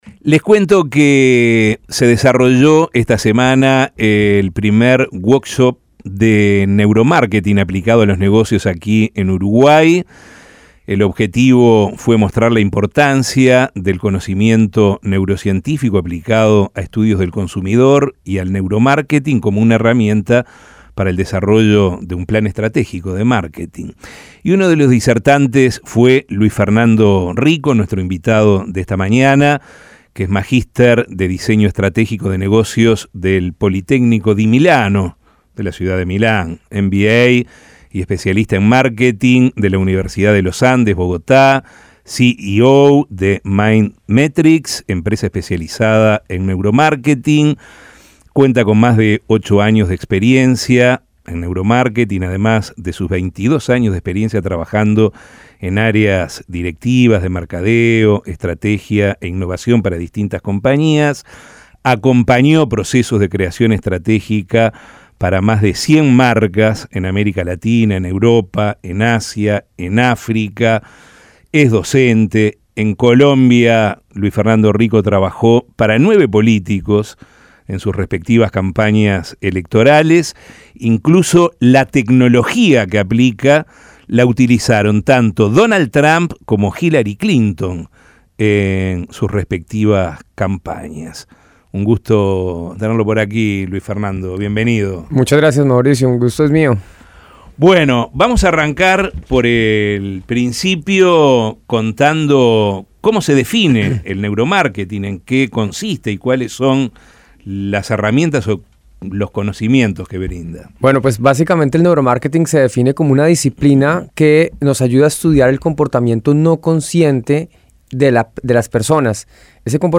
Escuche la entrevista en La Mañana